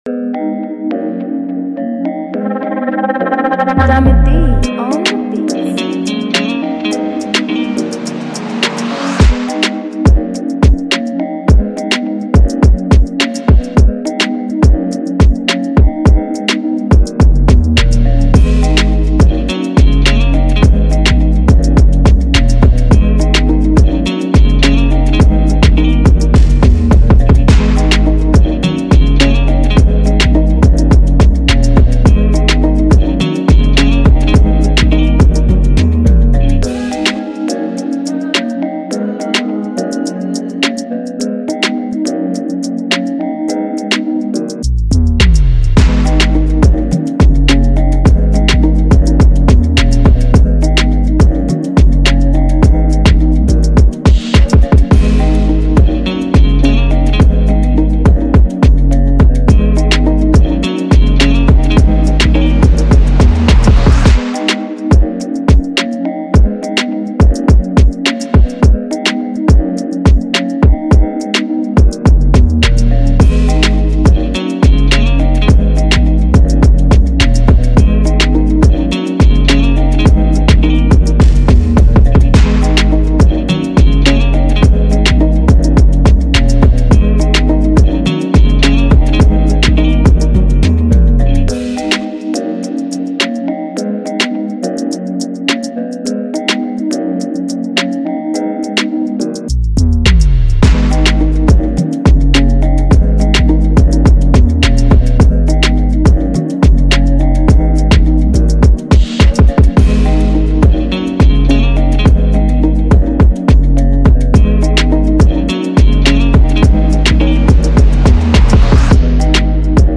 2021-09-02 1 Instrumentals 0
instrumental Free beat